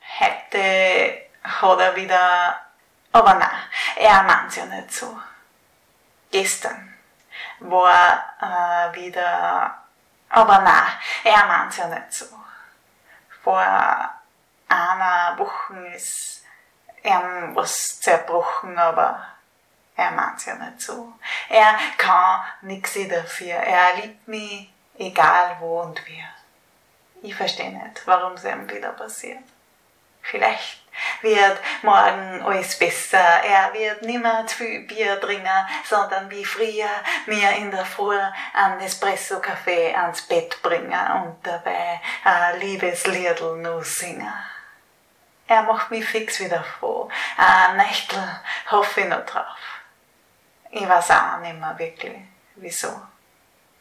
Aufgabe war es, einen Dialekt-Text mit 100 Wörtern zu verfassen.